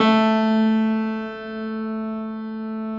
53g-pno09-A1.wav